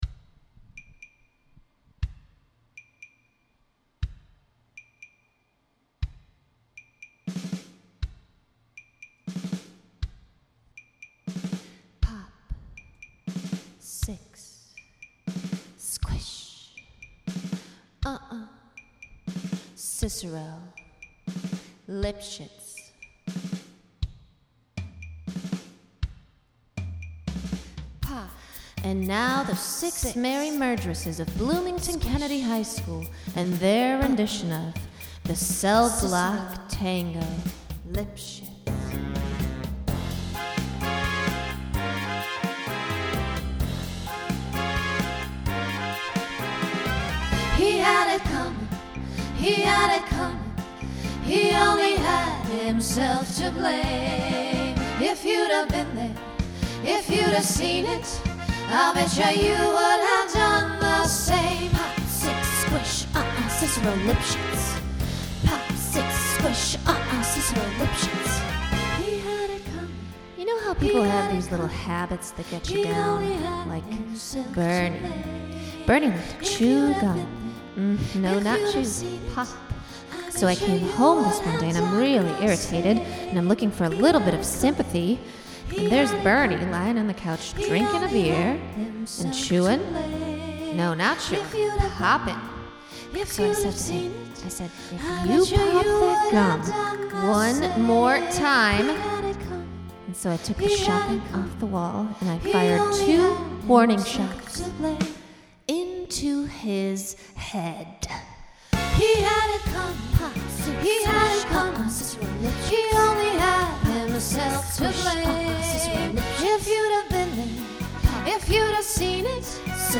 Genre Broadway/Film Instrumental combo
Story/Theme Voicing SSA